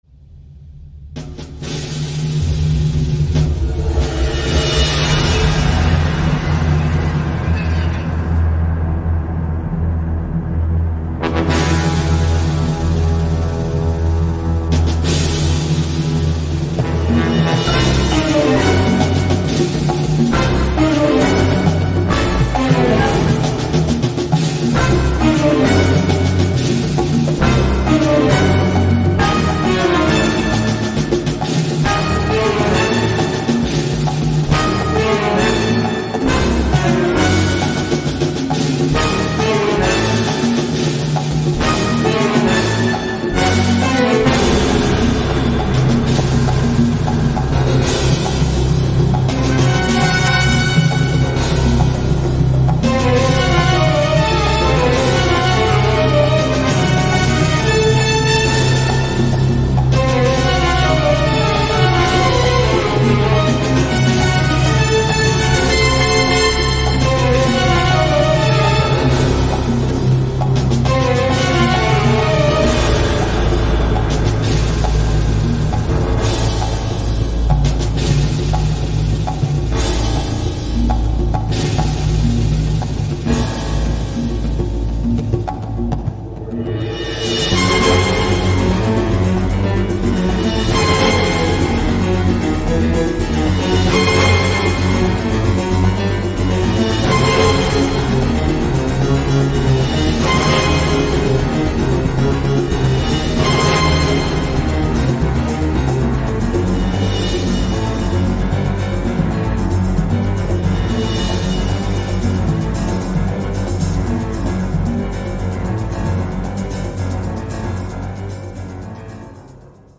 Malgré des synthés qui tâchent
les cuivres sonnent de manière abominable !!!
petite formation orchestrale.
créer des sons pour évoquer l’univers carcéral stressant